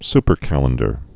(spər-kălən-dər)